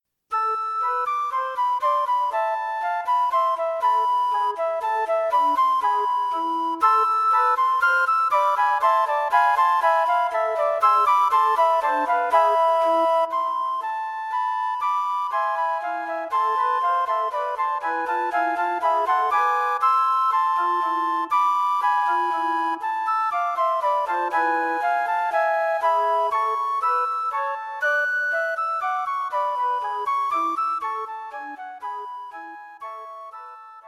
Flute Trio for Concert performance
A suite of three modern, amusing pieces for Flute Trio.
Flute 1, Flute 2 and Alto Flute
Three devilishly funny little works.